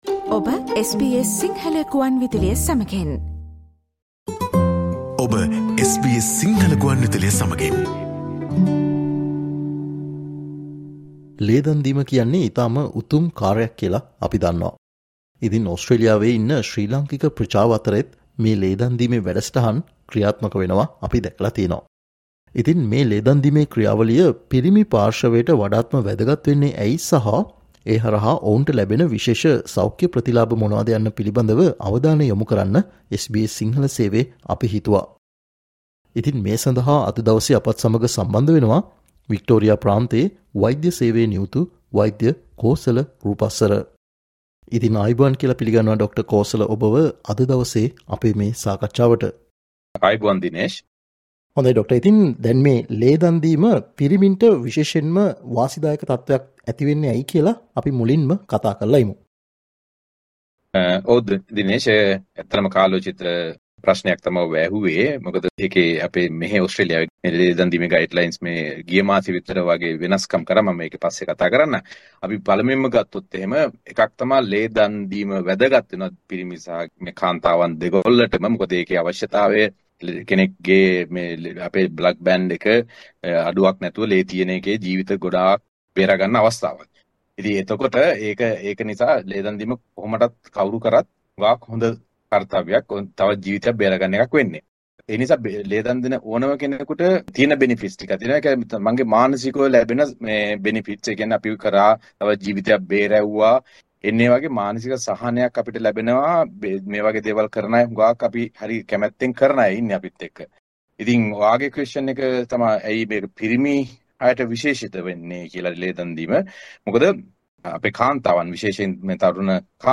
ඉතින් මේ ලේ දන්දීමේ ක්‍රියාවලිය පිරිමි පාර්ශවයට වඩාත් වැදගත් වන්නේ ඇයි කියන කාරනාව සම්බන්ධයෙන් සොයා බලන්න SBS සිංහල සේවයේ අපි හිතුවා. ලේ දන්දීම හරහා පිරිමි පාර්ශවයට ලැබෙන විශේෂ සෞඛය ප්‍රතිලාභ පිළිබඳව SBS සිංහල සේවය සිදුකල සාකච්චාවට සවන් දෙන්න